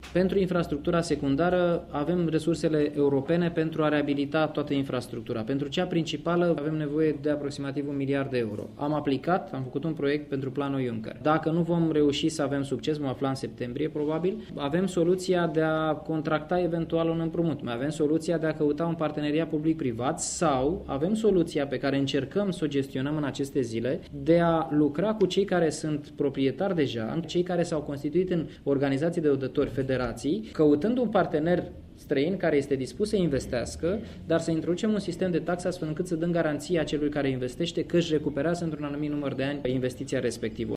Deocamdată problema este parţial rezolvată, a explicat la Arad ministrul agriculturii, Daniel Constantin.